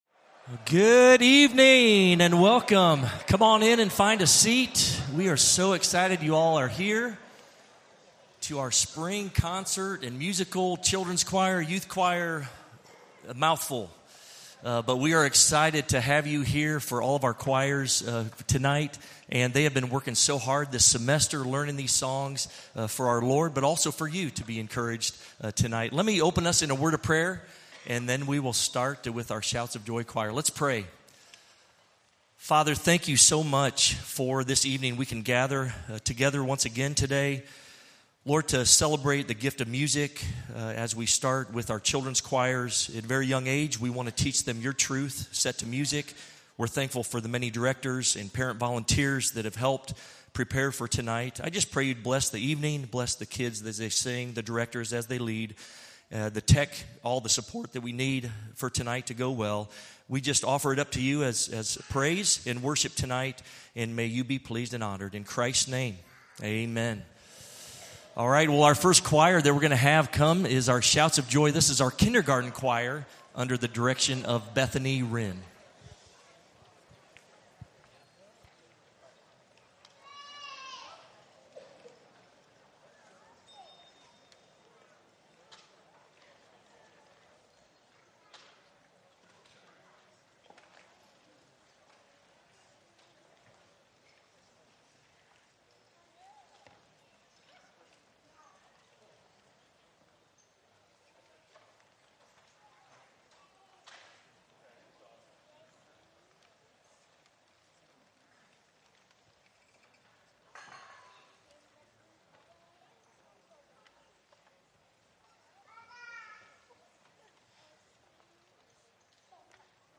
Children's Choirs - Spring Concert and Musical - 2025